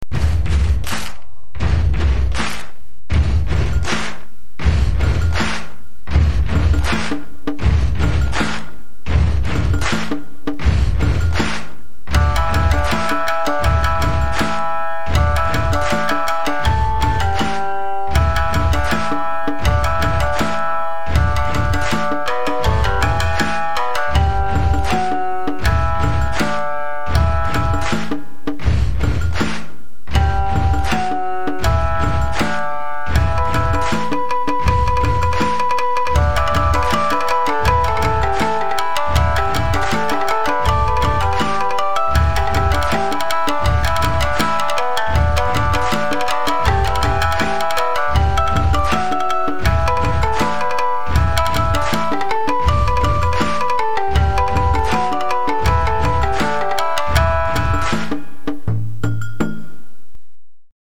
（カラオケ）仮歌入りもありますが（以下同様）(^^;